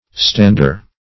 Stander \Stand"er\ (-[~e]r), n.